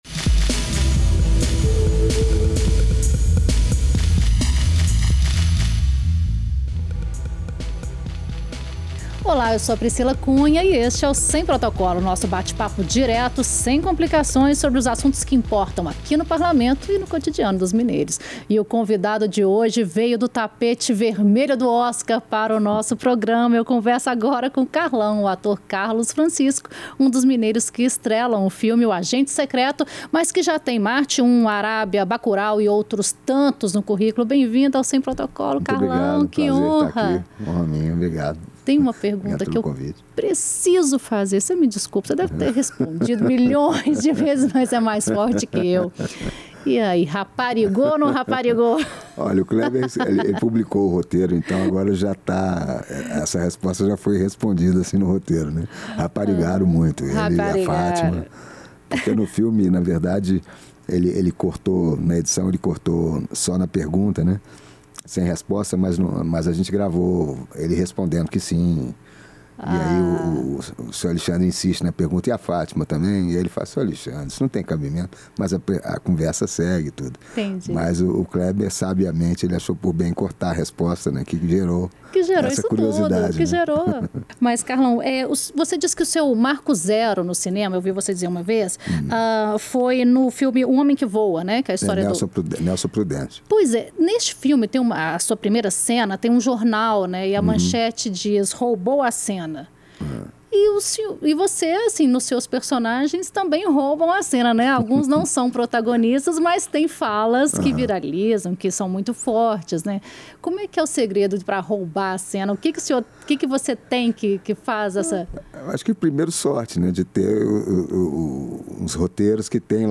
Neste bate-papo, o quilombola, que cresceu em Santa Teresa e se formou no teatro, fala sobre o sucesso de filmes que estrelou como "O Agente Secreto", "Marte Um" e "Bacurau", e defende a diversidade de raças, gêneros e ideias que tem ganhado destaque nas novas produções nacionais.